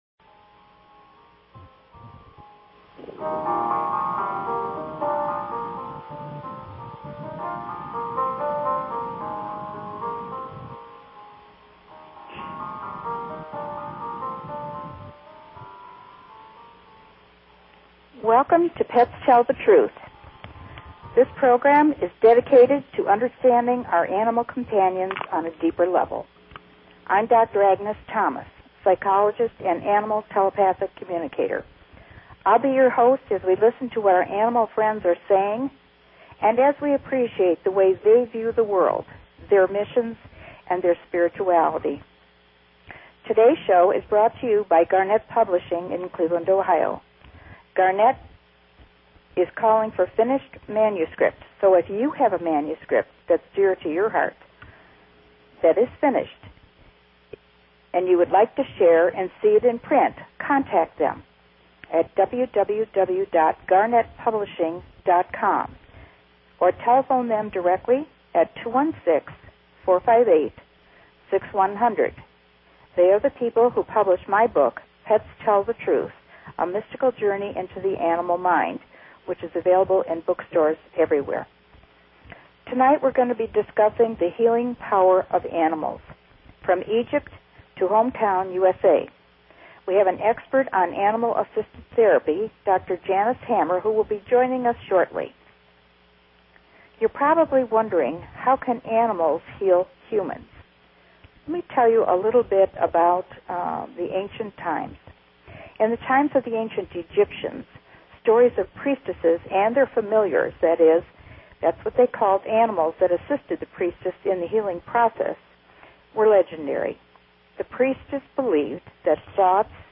Talk Show Episode, Audio Podcast, Pets_Tell_The_Truth and Courtesy of BBS Radio on , show guests , about , categorized as
Interview w